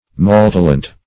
Search Result for " maltalent" : The Collaborative International Dictionary of English v.0.48: Maltalent \Mal"ta*lent\, n. [F. See Malice , and Talent .] Ill will; malice.